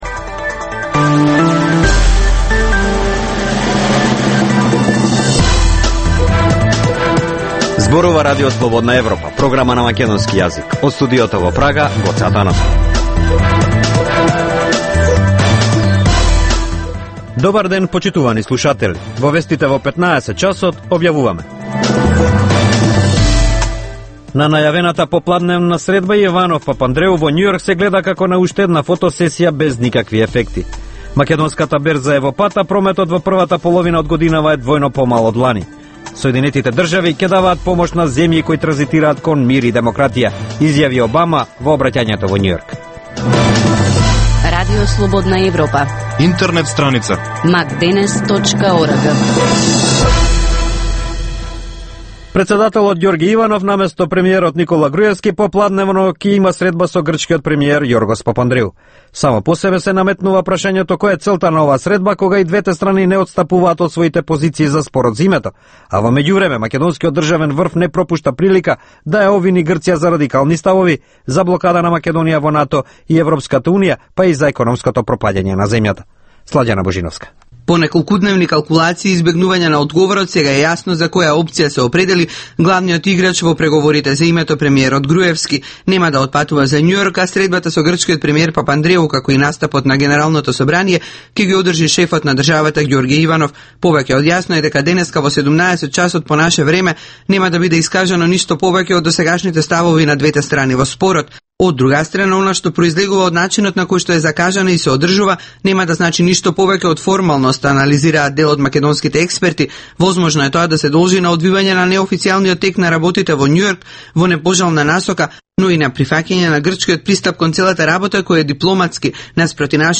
Информативна емисија, секој ден од студиото во Прага. Вести, актуелности и анализи за случувања во Македонија на Балканот и во светот.